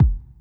000-kick.wav